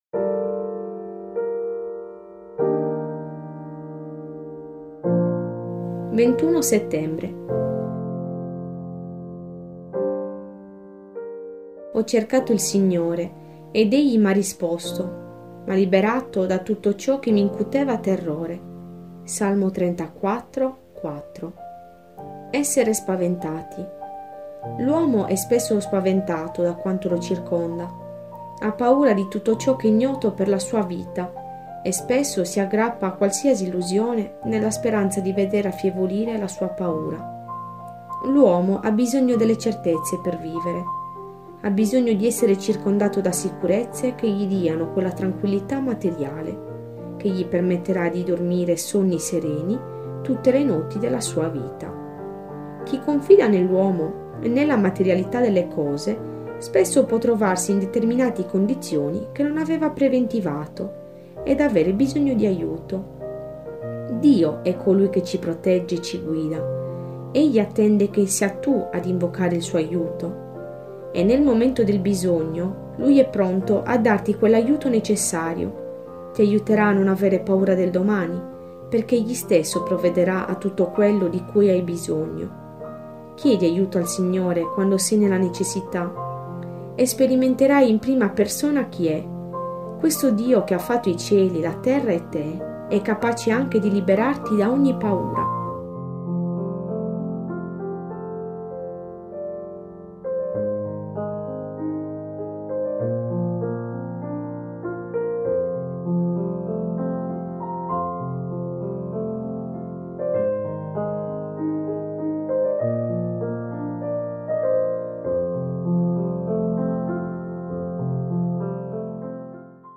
MEDITAZIONE GIORNALIERA